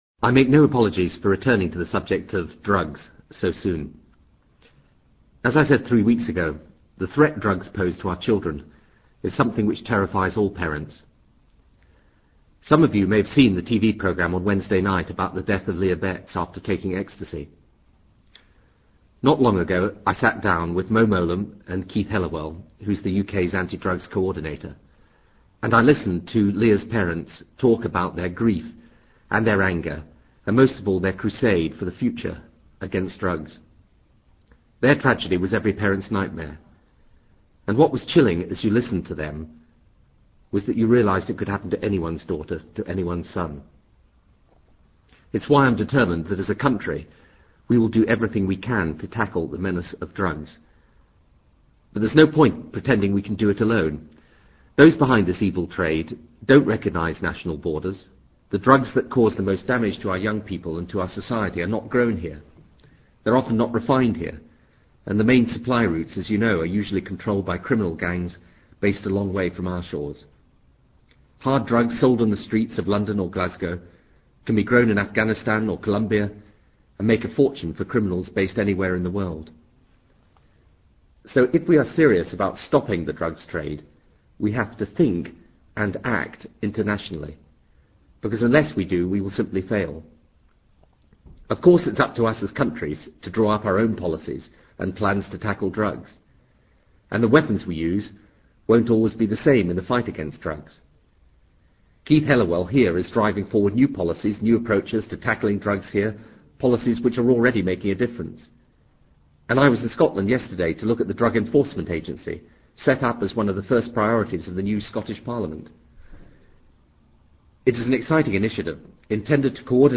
布莱尔首相演讲:Drugs[2]
TRANSCRIPT OF THE PRIME MINISTER'S BROADCAST ON 10 MARCH 2000